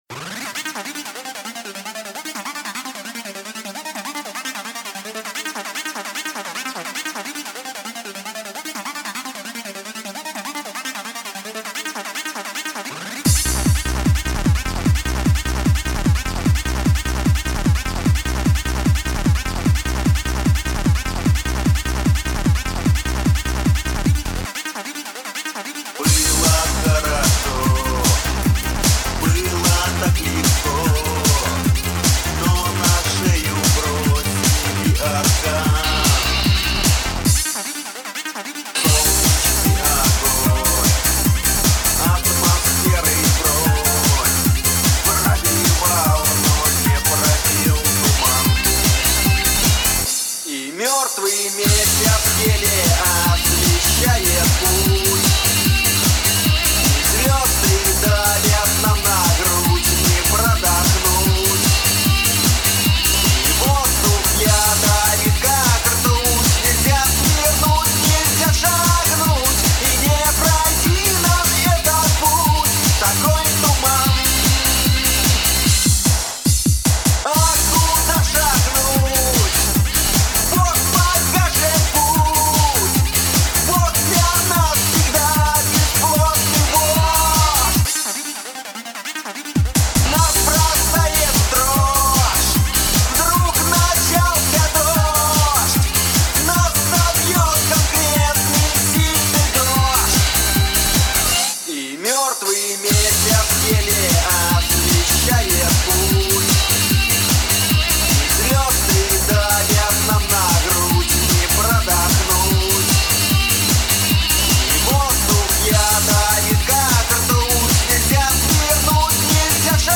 Dance mix